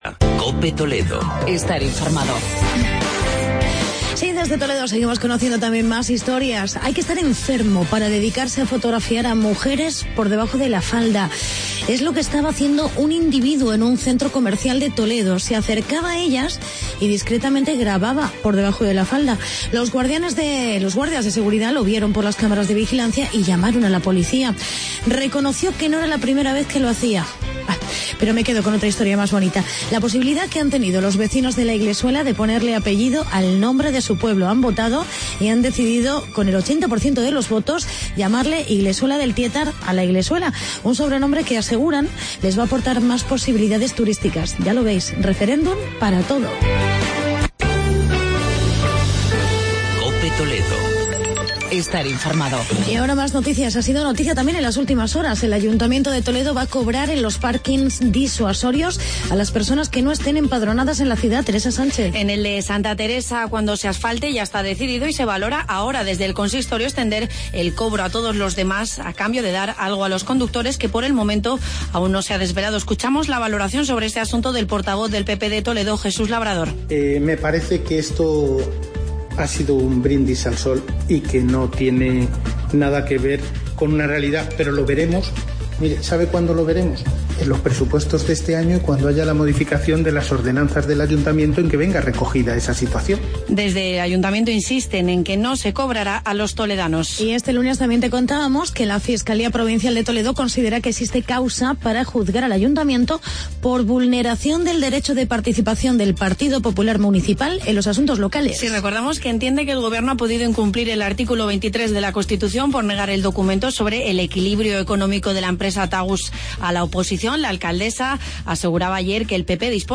Actualidad y entrevista con el triatleta y olímpico Fernando Alarza, recién proclamado campeón de España de Triatlón.